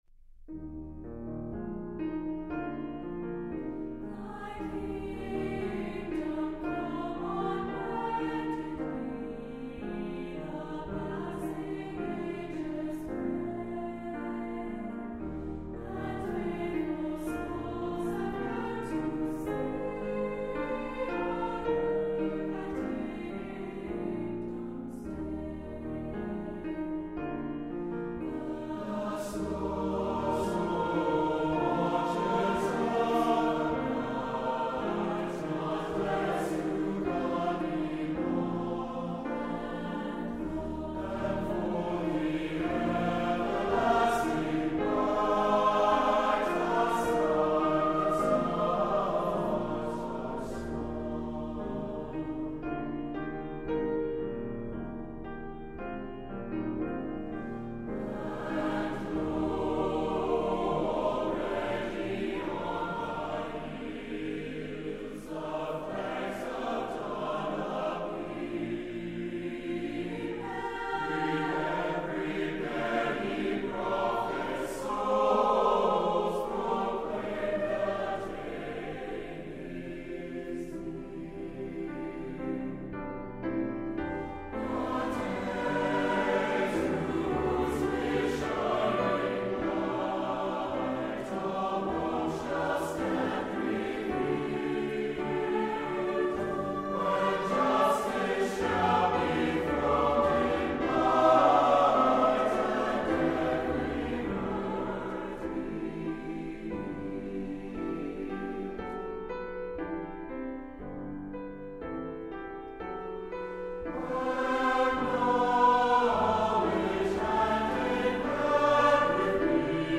SATB (4 voices mixed).
Hymn (sacred).
Tonality: E flat major